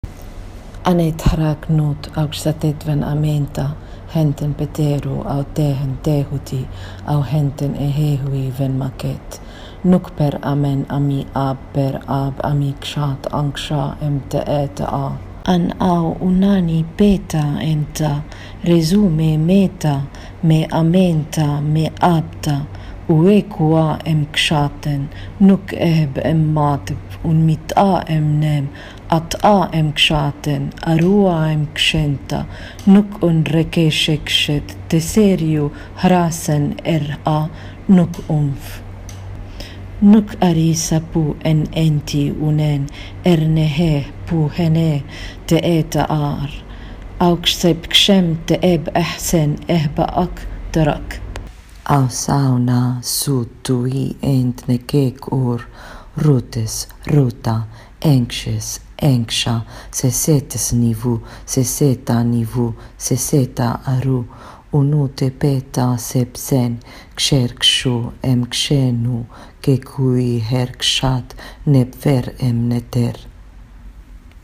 Reciting of the soul prayer: